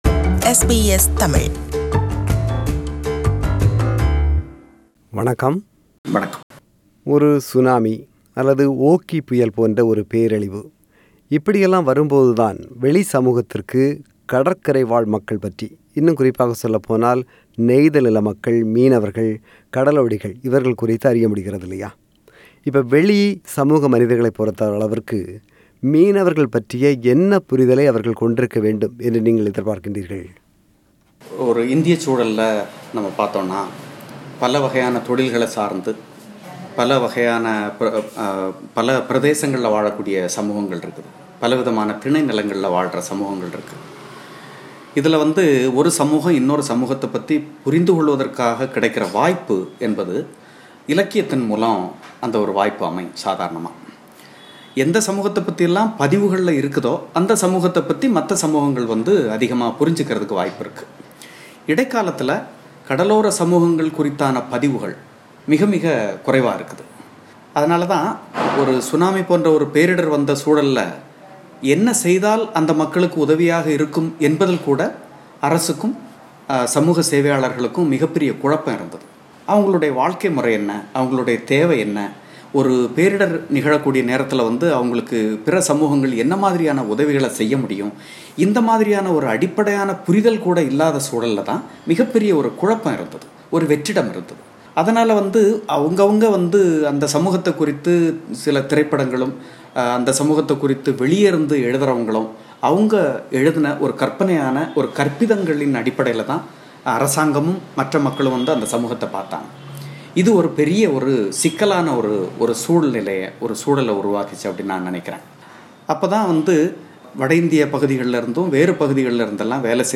இல்லம் சென்று அவரை சந்தித்து நாம் நடத்திய உரையாடலின் முதற்பாகம்.